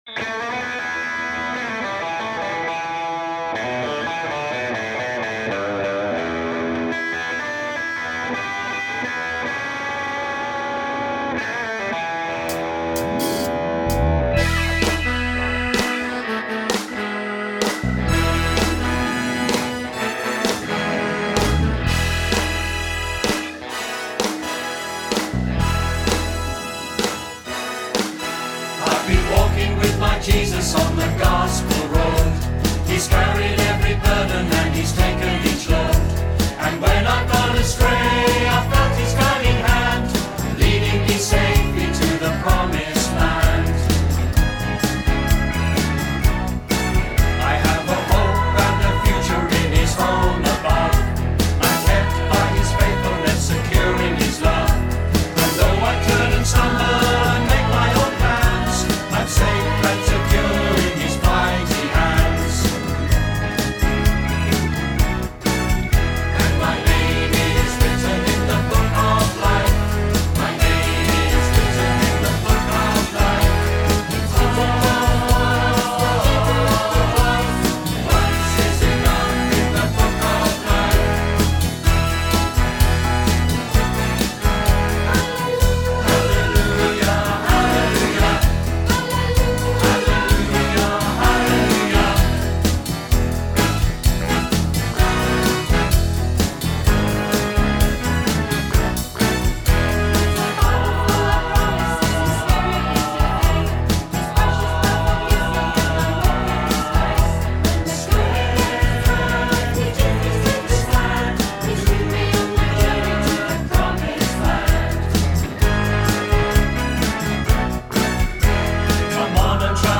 NEWLY RELEASED CD of Musical only £6 + P&P